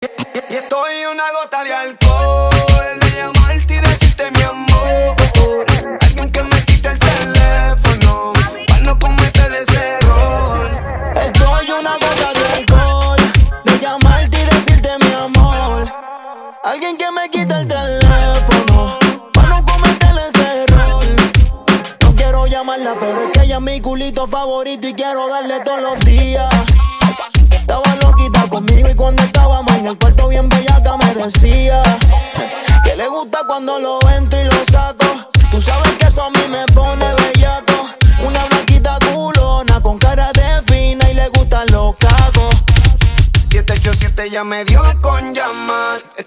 Categoría Pop